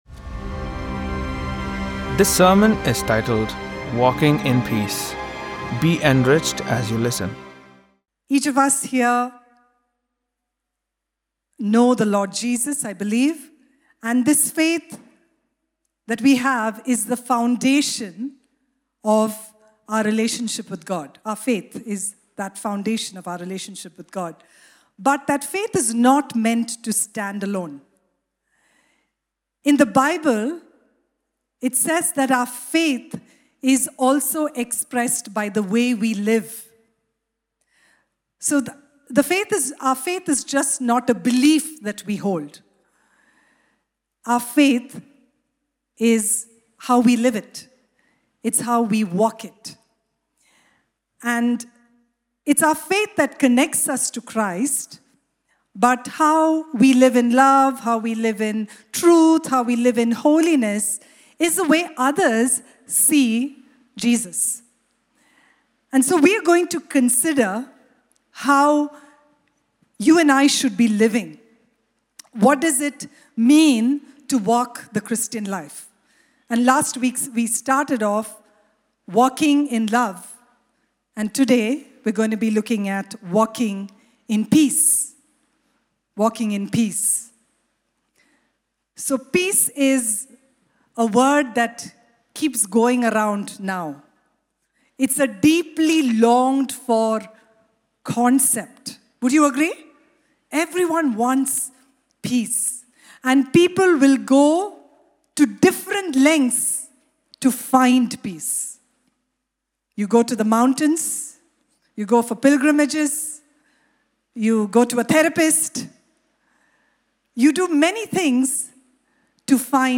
All Peoples Church Sermons Podcast - Walking in Peace | Free Listening on Podbean App